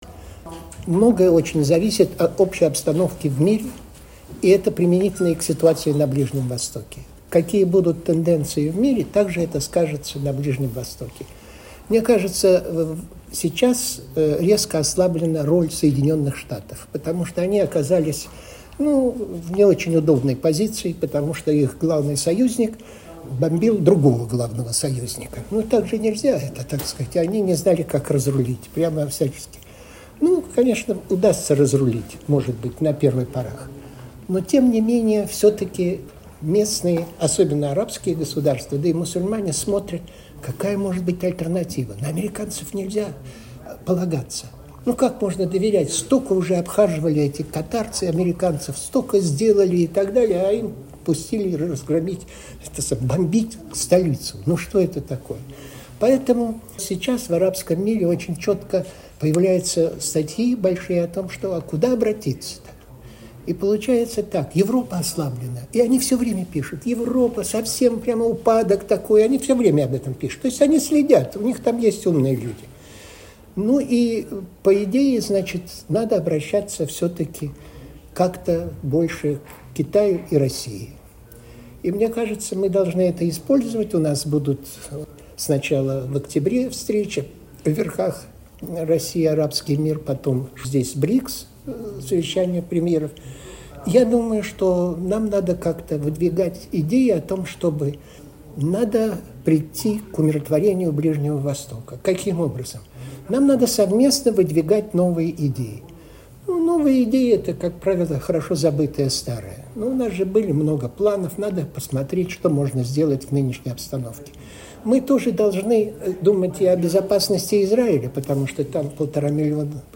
ГЛАВНАЯ > Актуальное интервью
Чрезвычайный и полномочный посол, директор Центра партнёрства цивилизаций Института международных исследований МГИМО(У) МИД России Вениамин Попов в интервью журналу «Международная жизнь» рассказал о ситуации на Ближнем Востоке: